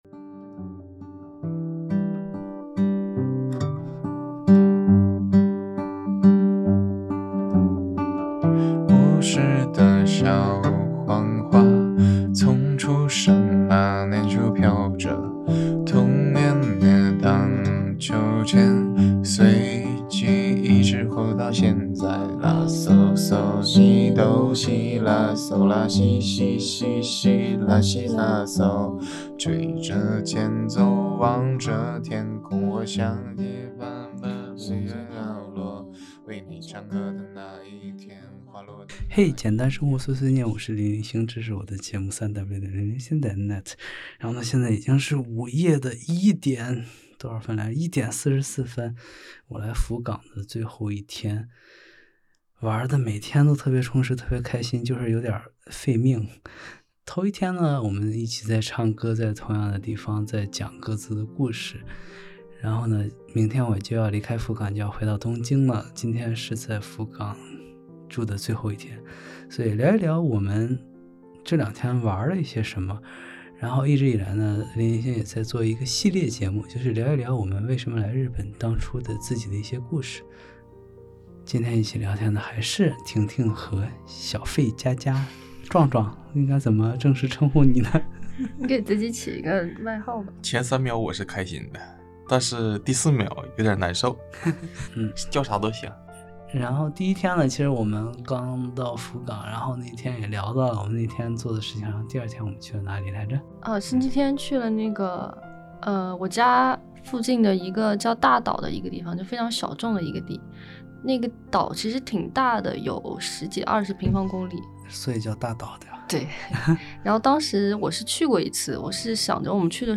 深夜节目，聊着聊着， 我们困得脑子已经不灵光，语无伦次了……